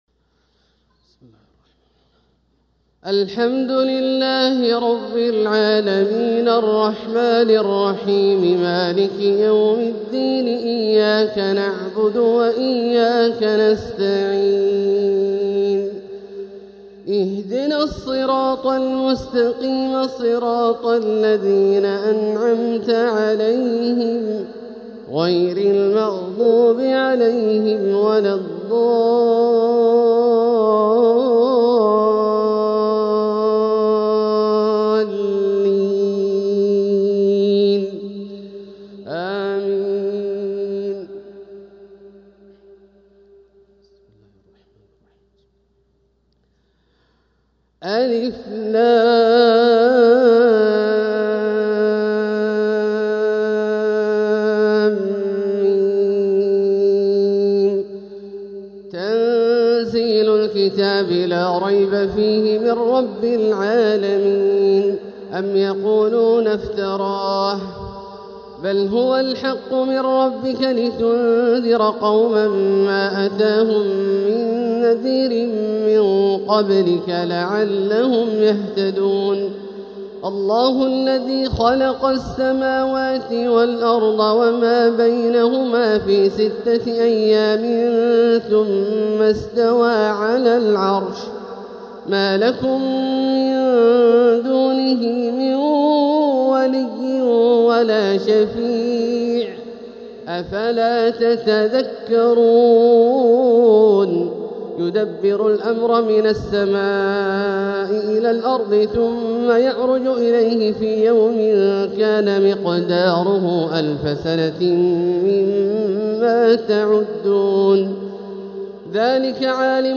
تلاوة لسورتي السجدة والإنسان | فجر الجمعة ٢٠ شوال ١٤٤٦ هـ > ١٤٤٦ هـ > الفروض - تلاوات عبدالله الجهني